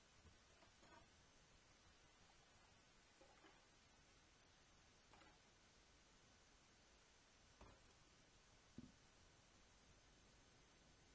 washingup
描述：Someone washing up the dishes in the kitchen sink. Includes sounds of water.Recorded with Zoom H4n.
标签： kitchen sink washingup water dishes tap drain
声道立体声